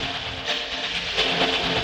Index of /musicradar/rhythmic-inspiration-samples/130bpm